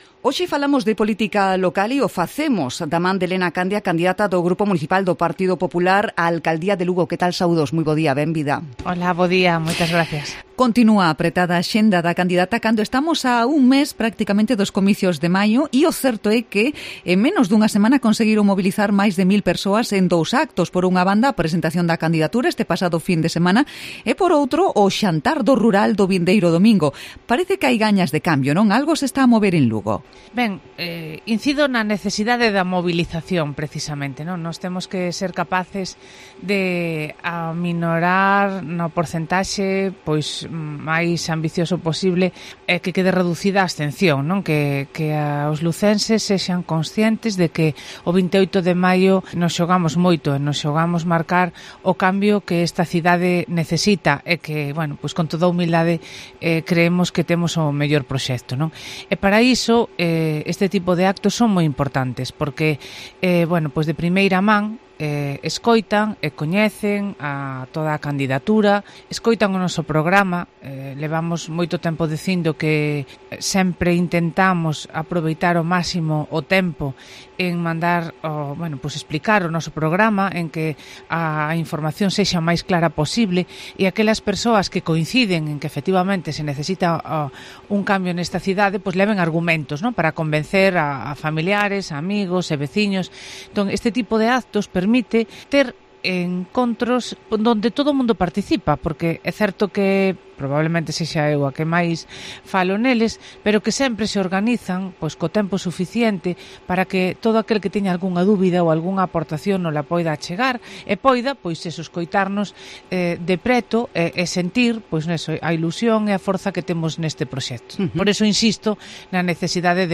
Entrevista a Elena Candia en Cope Lugo. 27 de abril